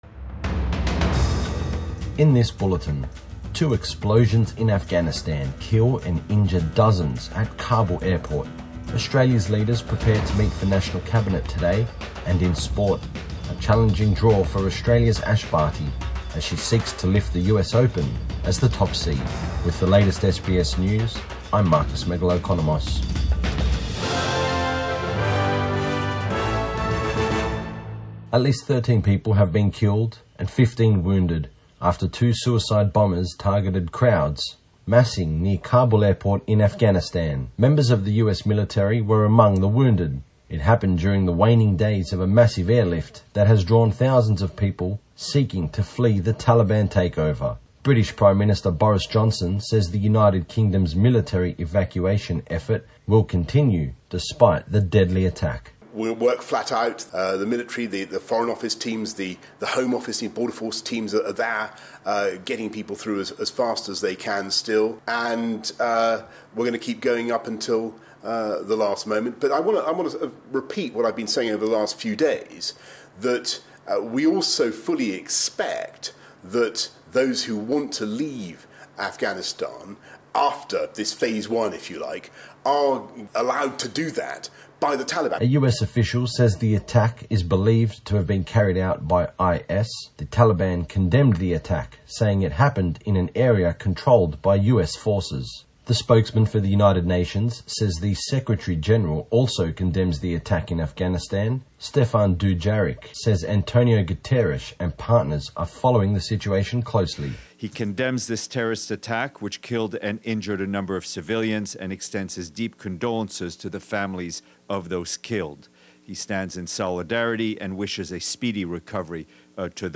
AM bulletin 27 August 2021